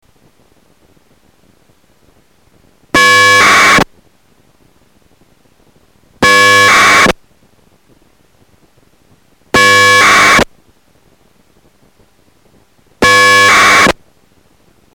srll_testsound.mp3